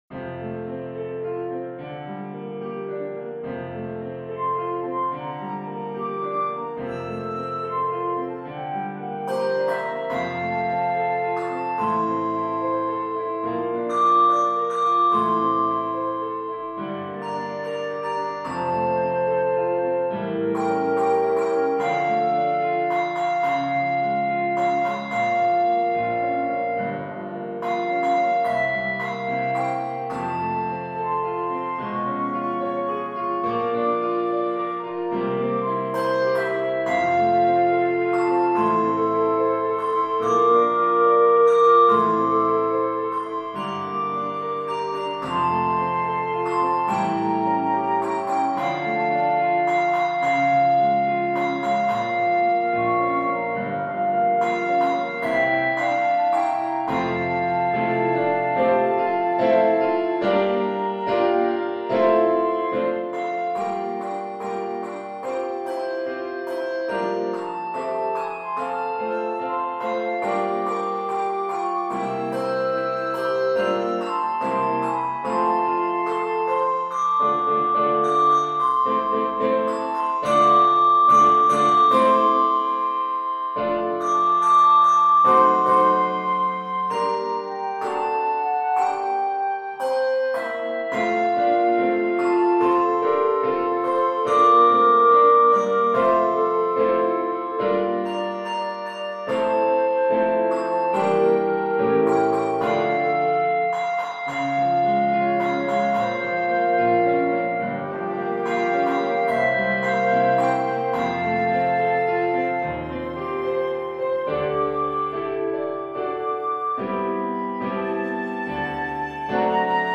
Solo Ringer
Jazz , Concert , Secular